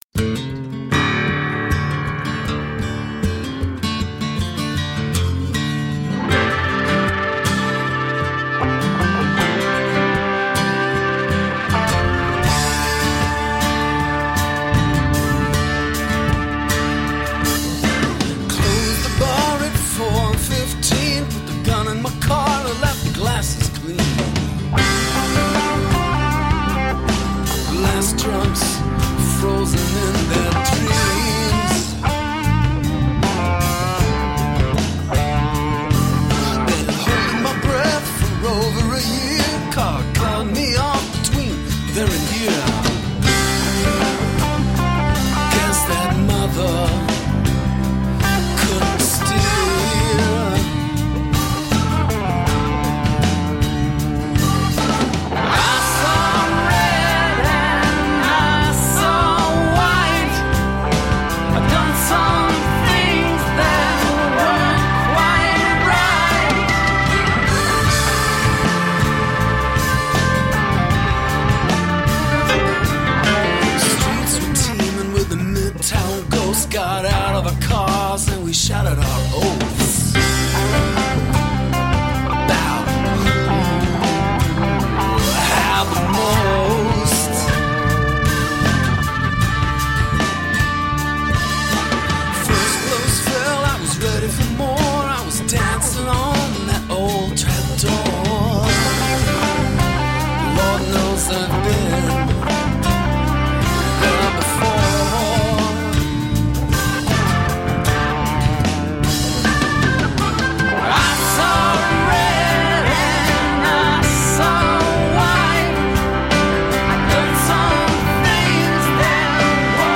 down-to-earth singer-songwriter
Tagged as: Alt Rock, Rock, Folk